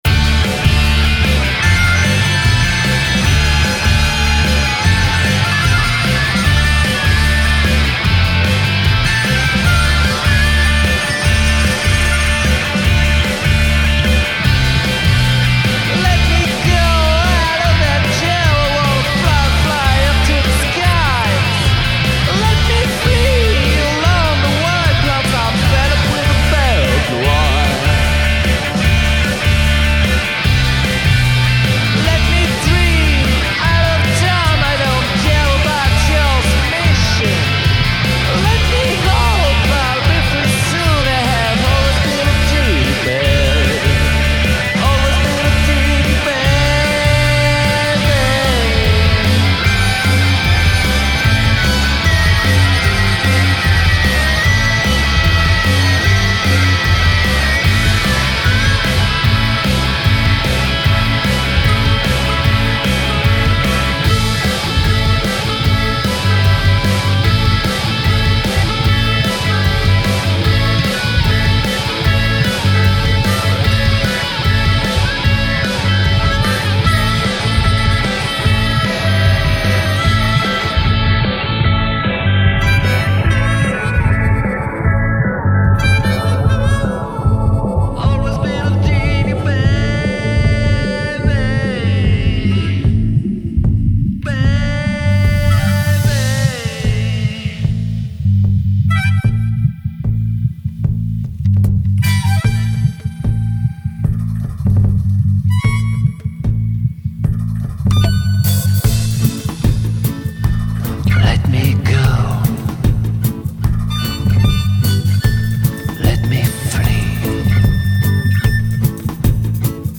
guitare, clavier, basse, programmation, voix
voix, harmonica, guitare, clavier, programmation, mixage